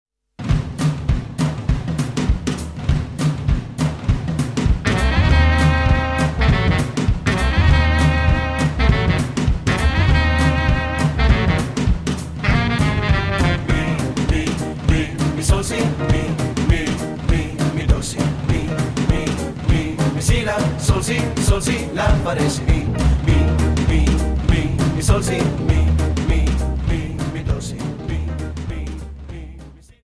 Dance: Quickstep 50 Song